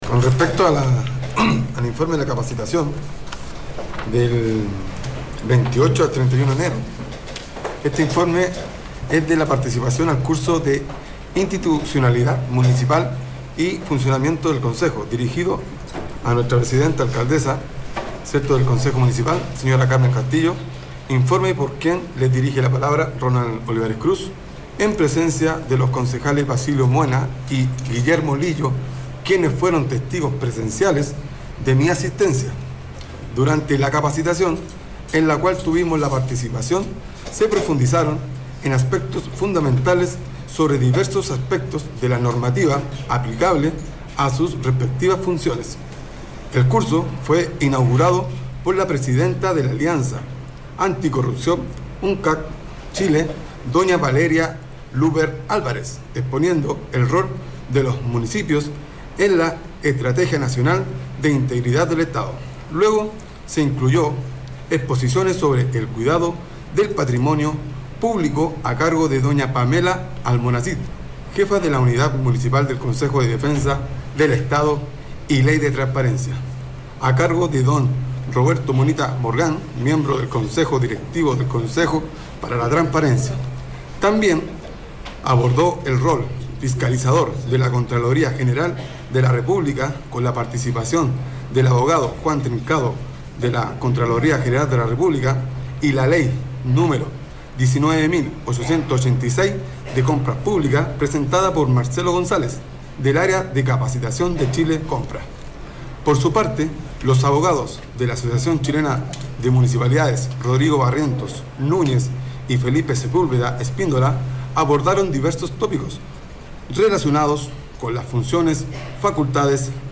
Es el Concejal Ronald Angelo Olivares Cruz, este martes en la sesión del Concejo de San Felipe.